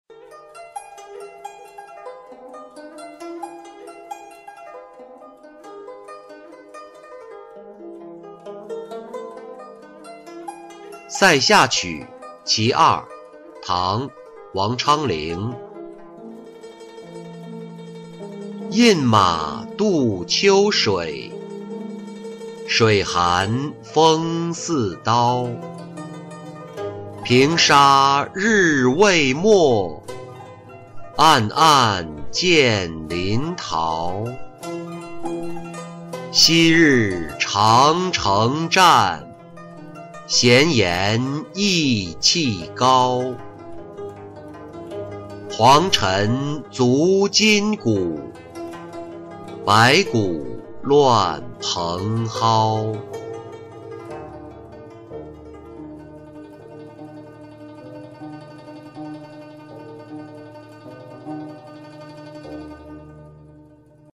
塞下曲 其二-音频朗读